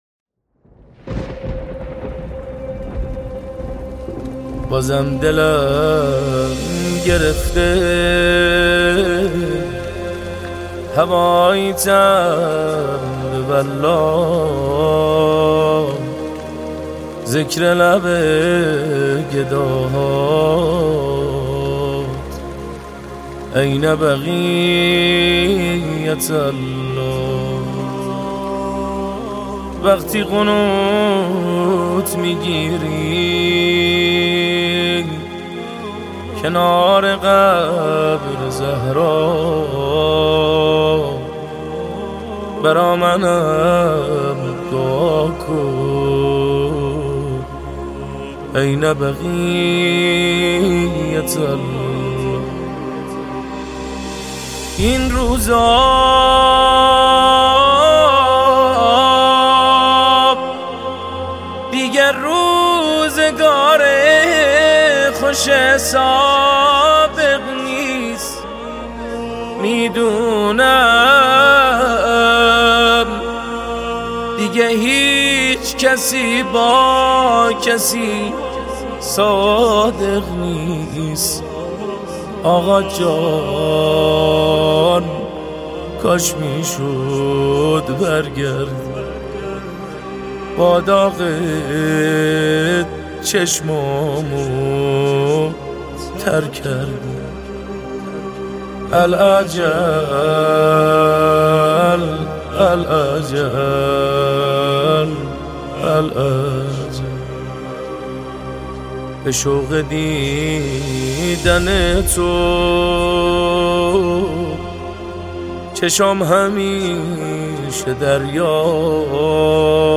نماهنگ مهدوی
با نوای دلنشین و سوزناک